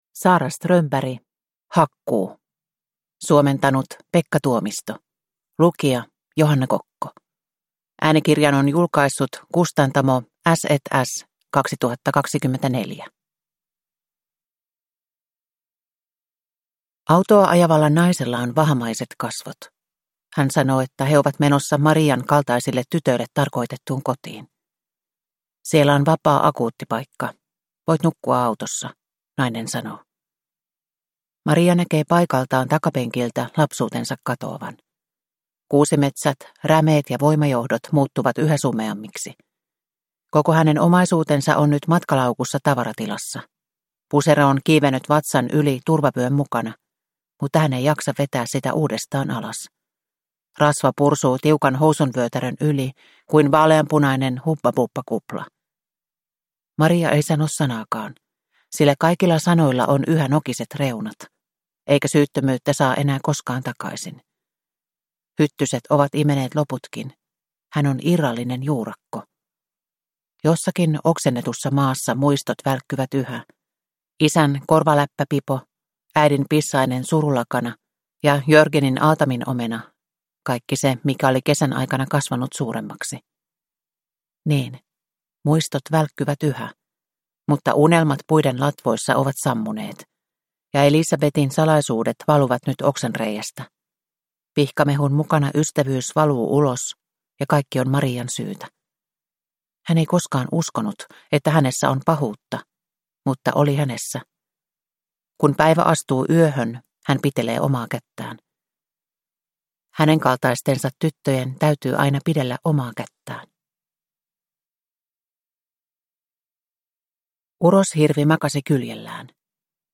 Hakkuu (ljudbok) av Sara Strömberg